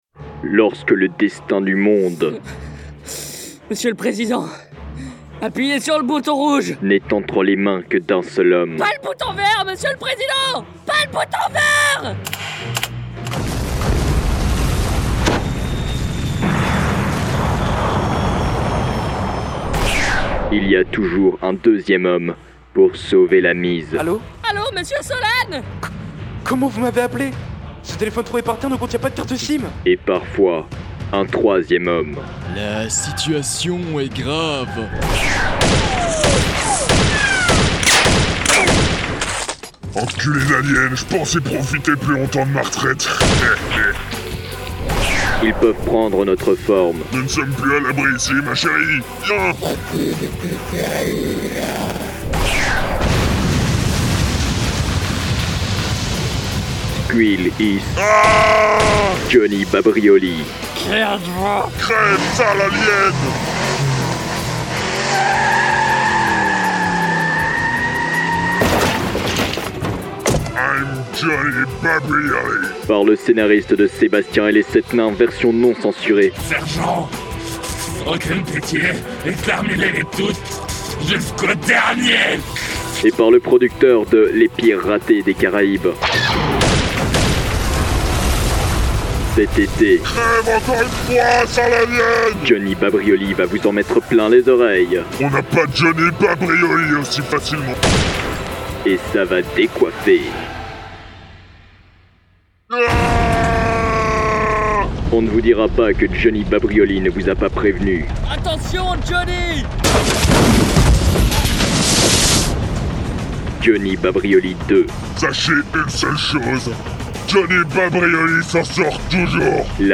Jeu de mixage - Bandes-annonces
• Utiliser un Deesser
• Utiliser une reverb